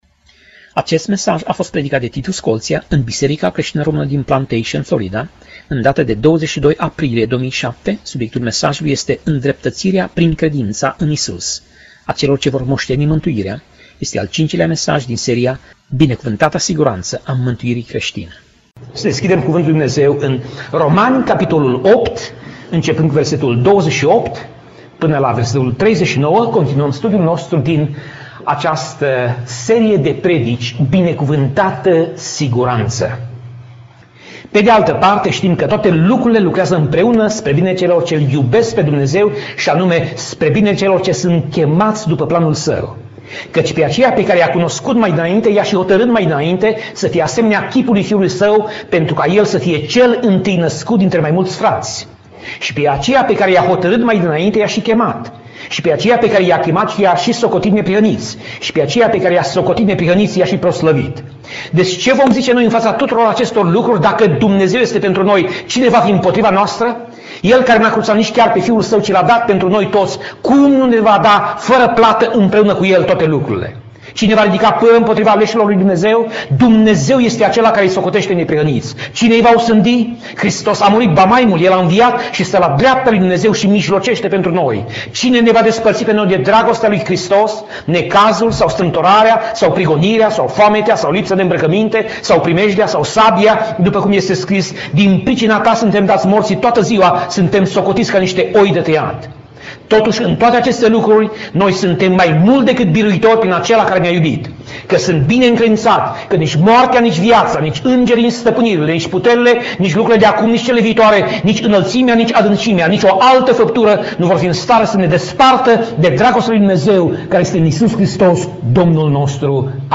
Pasaj Biblie: Romani 8:28 - Romani 8:30 Tip Mesaj: Predica